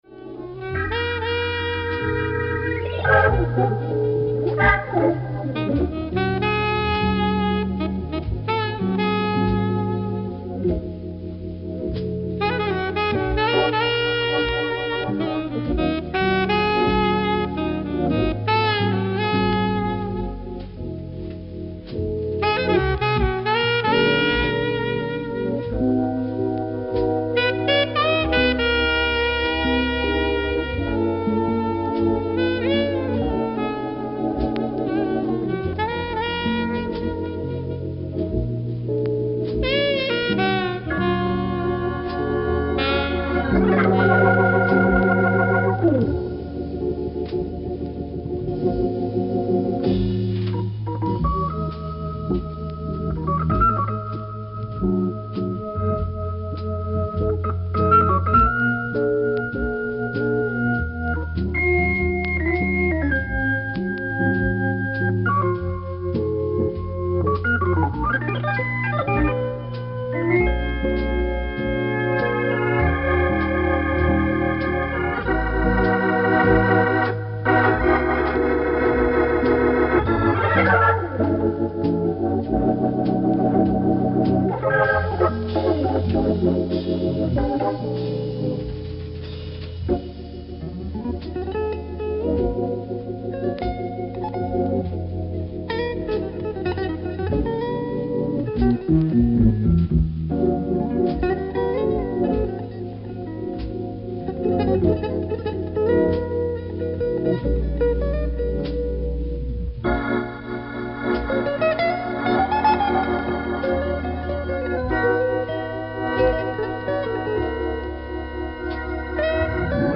Je trouve également une certaine mélancolie dans son jeu.